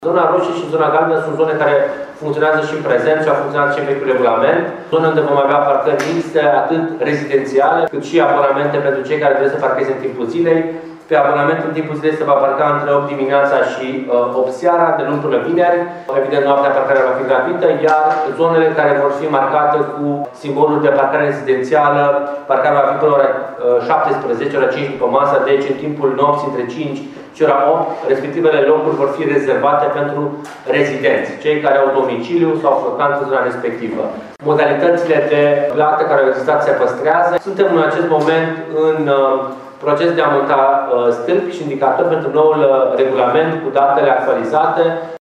În zonele roșie și galbenă va fi permisă parcarea atât pentru rezidenți, cât și pentru uz general, dar în zona verde – centrală – parcarea va fi taxată la oră, spune viceprimarul Ruben Lațcău.